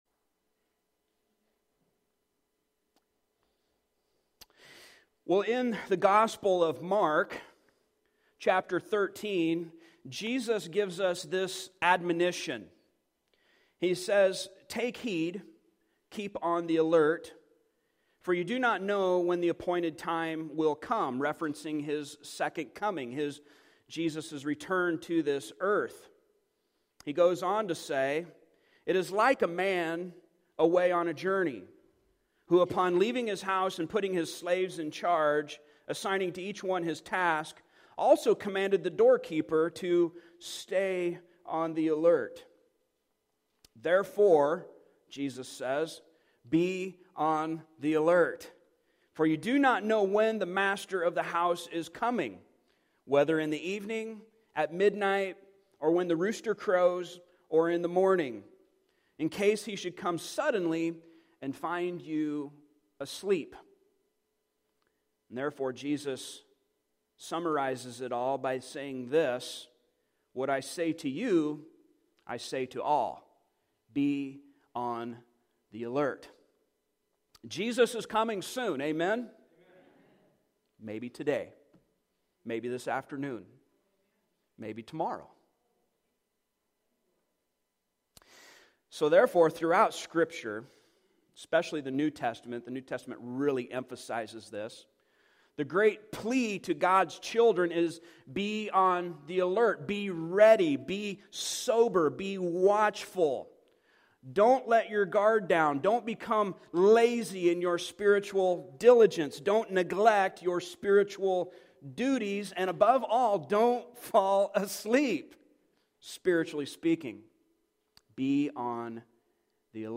Passage: John 9:13-34 Service Type: Sunday Morning Topics